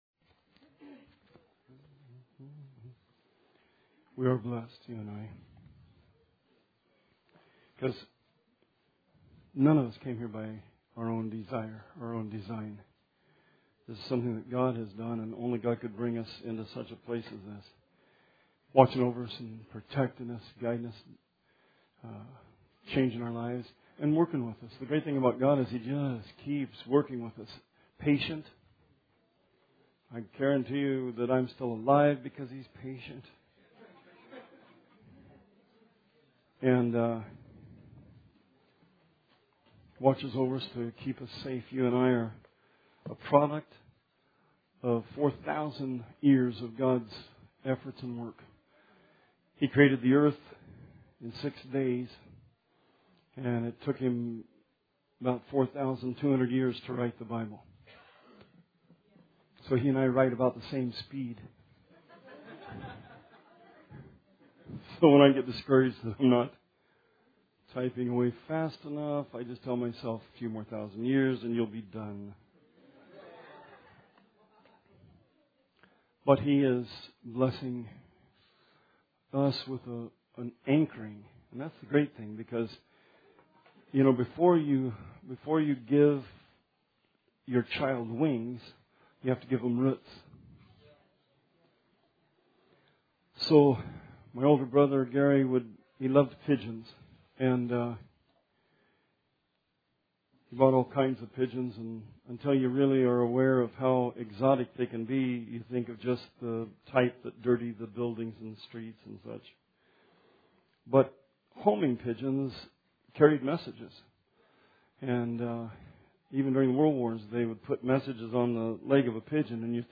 This sermon focuses on the garden walk, where we walk with God as Adam did in Genesis 2. We need to be restored by faith to the relationship that God had with Adam, a Father/Son relationship.